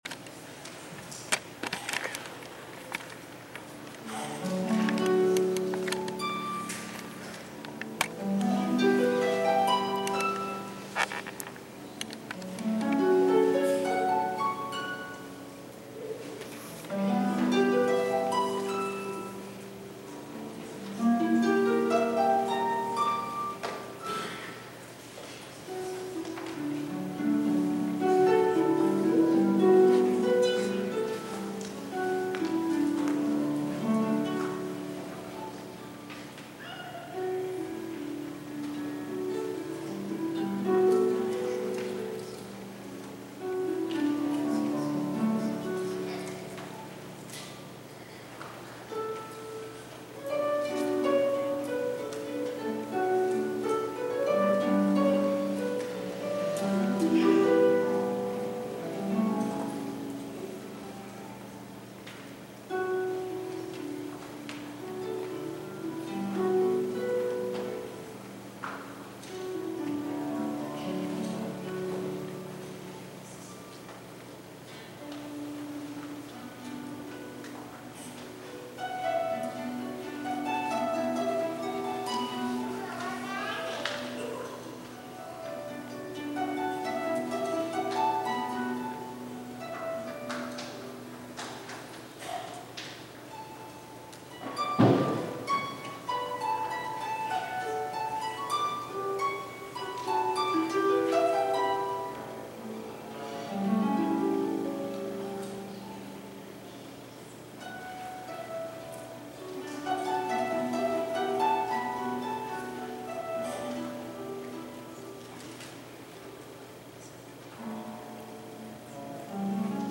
Following the Worship Service and the Anniversary Dinner, the day’s events Sunday concluded with a Music Celebration service.
harp solo